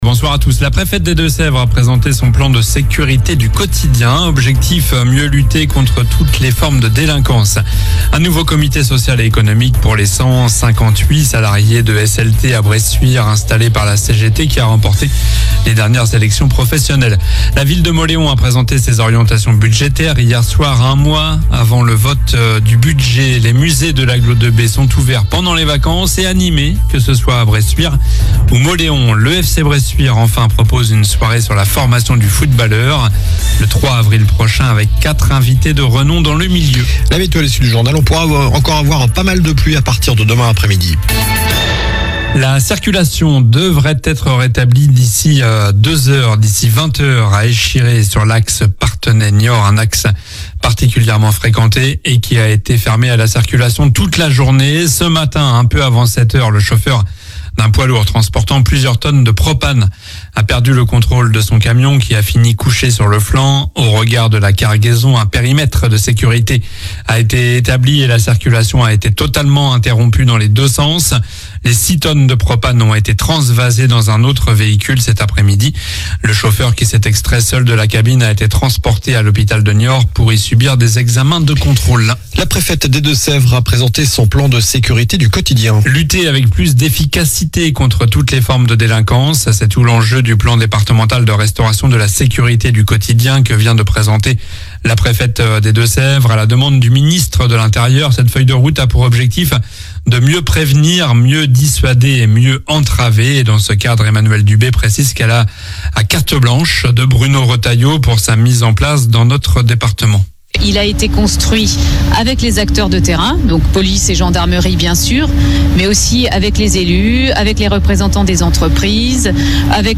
Journal du mardi 25 février (soir)